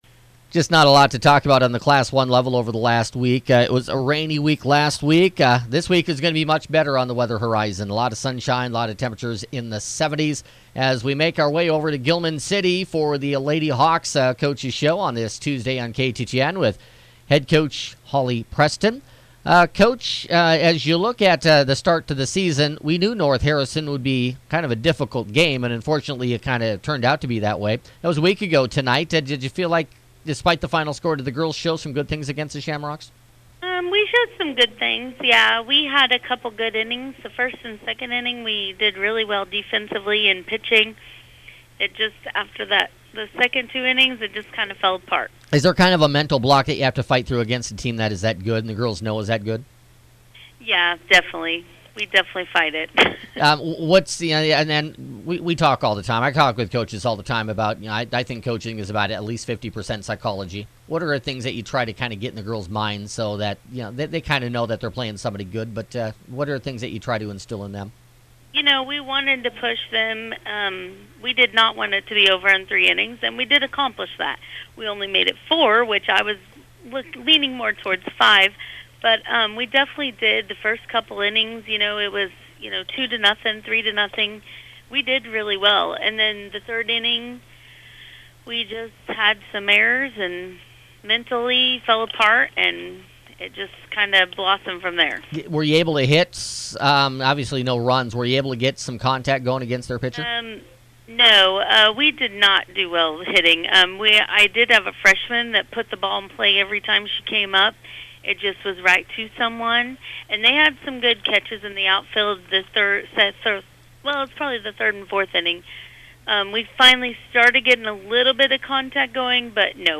Originally airing at 5:50 on Tuesday afternoon on KTTN FM 92.3